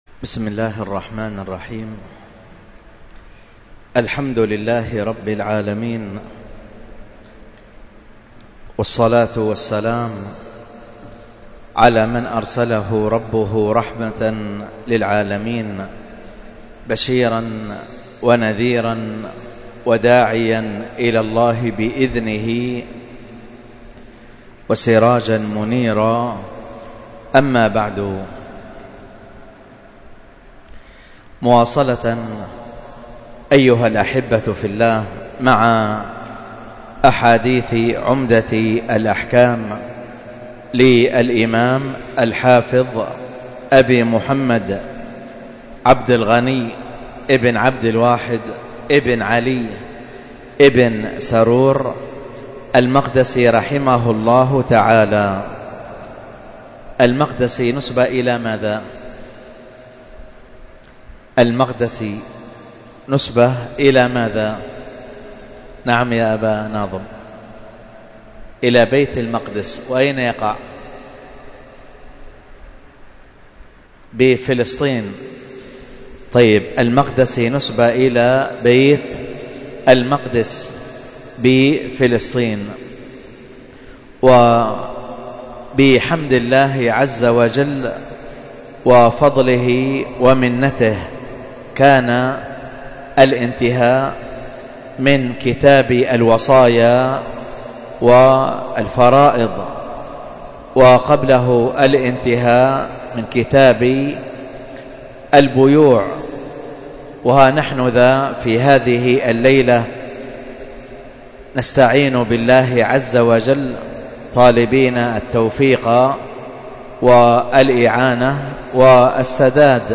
شرح كتاب الطهارة 37 تحميل الدرس في شرح كتاب الطهارة 37، الدرس السابع والثلاثون (الثالث زوال العقل وهو نوعان ...